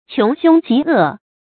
注音：ㄑㄩㄥˊ ㄒㄩㄥ ㄐㄧˊ ㄜˋ
窮兇極惡的讀法